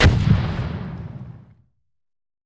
Single explosion with heavy debris.